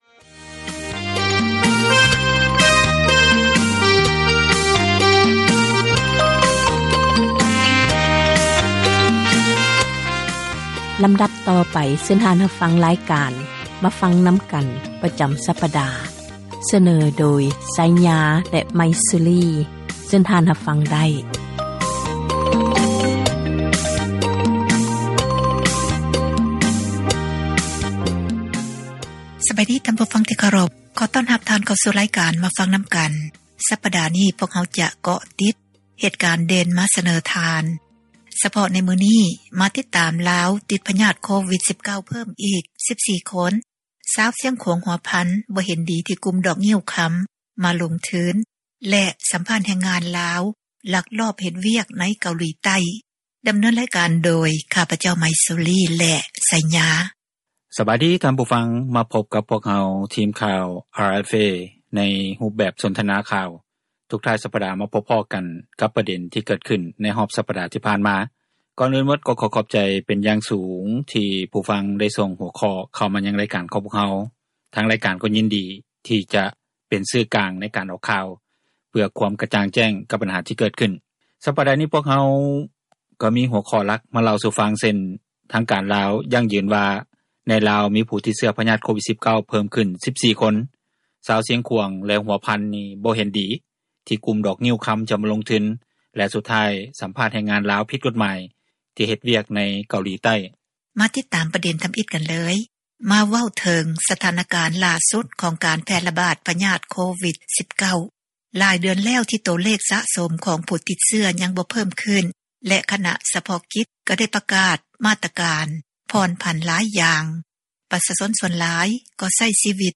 ການສົນທະນາ